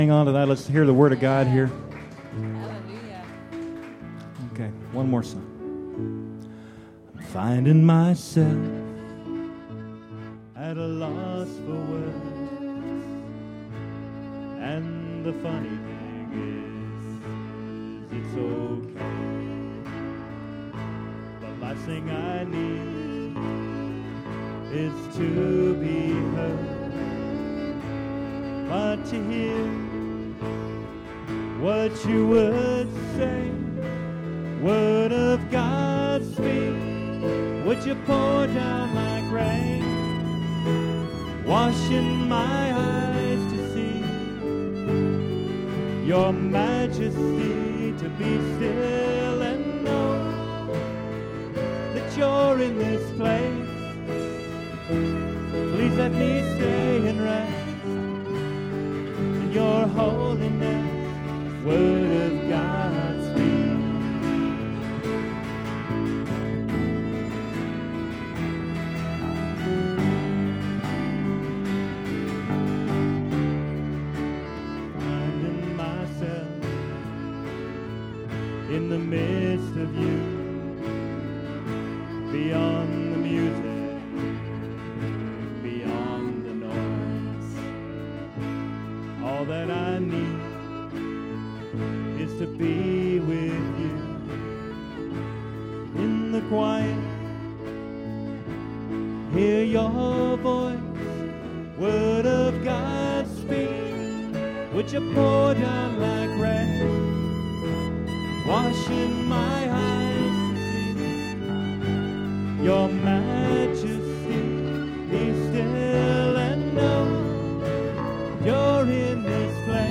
Sunday March 13th Sermon Notes & Audio | Potters Hands Ministries